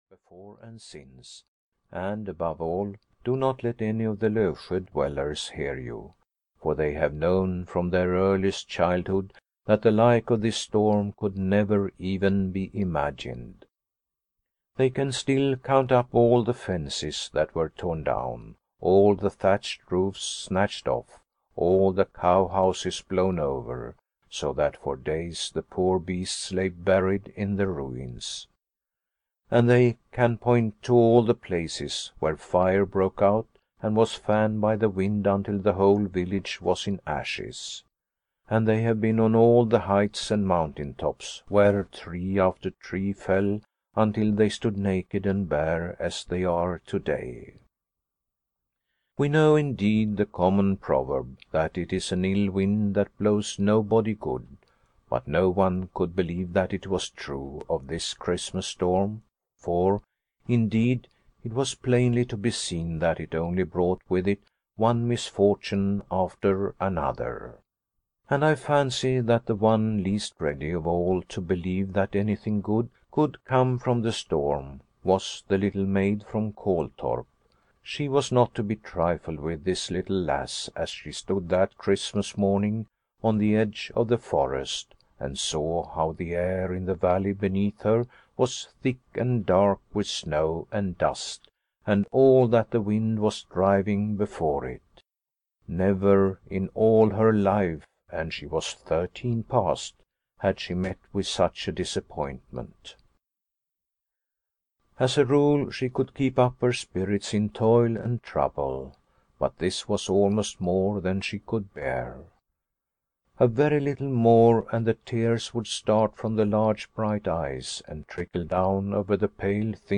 Liliecrona's Home (EN) audiokniha
Ukázka z knihy